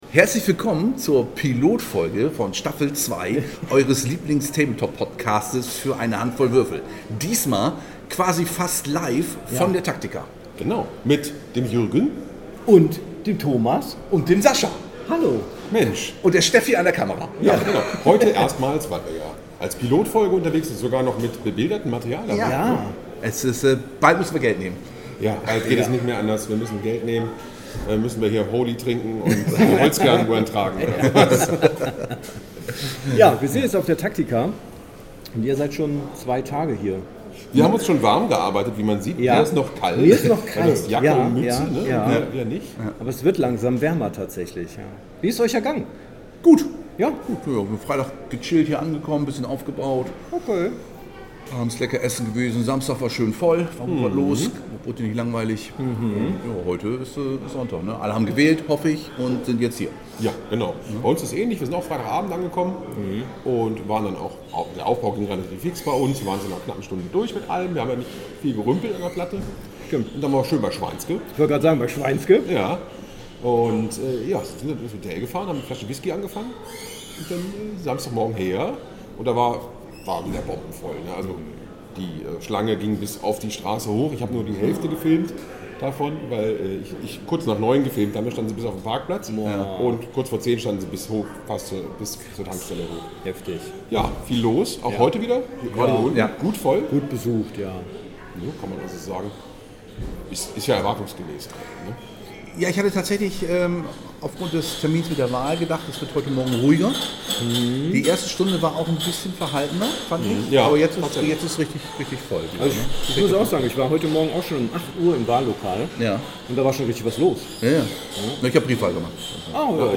Live von der Tactica 2025 ~ Für eine Hand voll Würfel Podcast